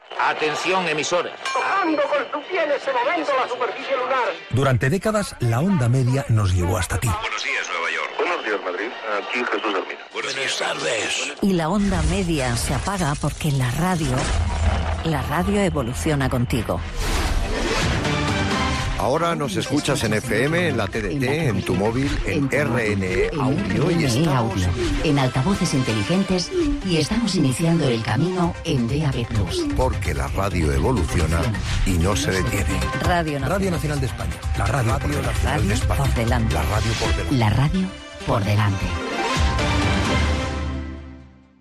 Tres promocions de l'apagada de les emissions en Ona Mitjana de RNE.